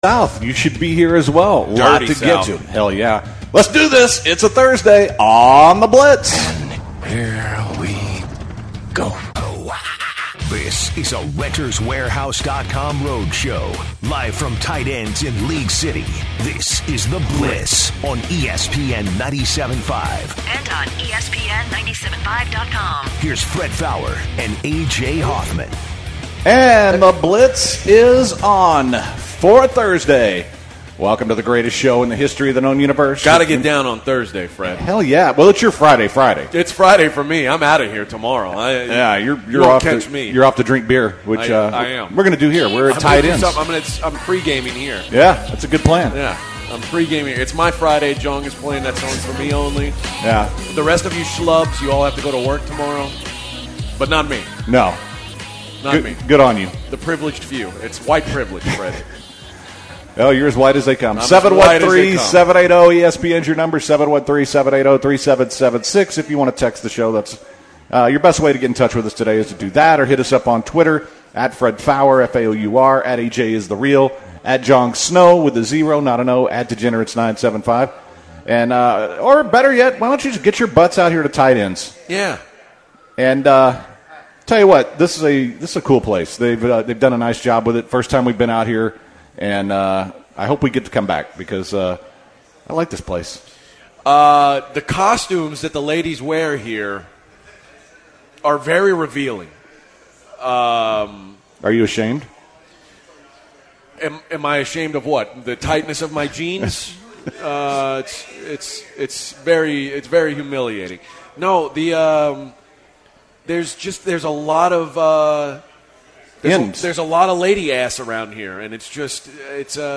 The guys are at Tight Ends for the Sam Adams contest and also Chris Harris join them talking some football.